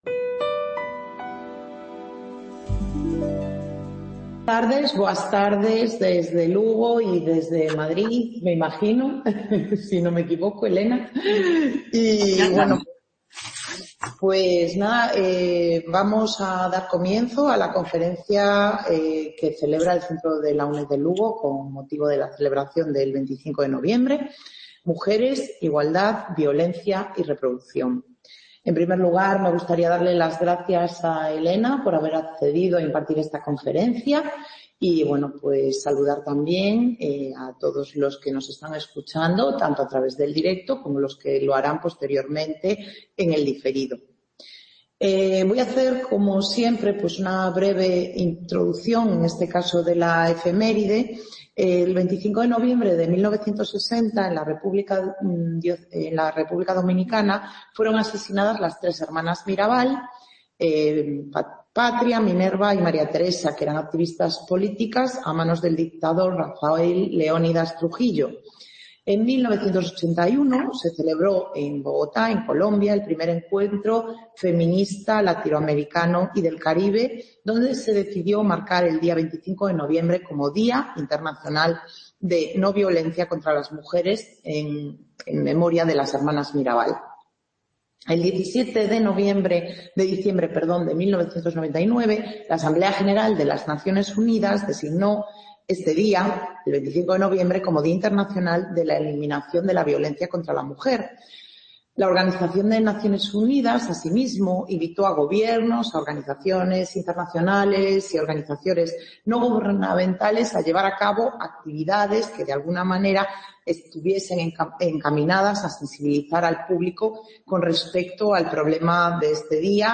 Conferencia: Mujeres, igualdad, violencia y…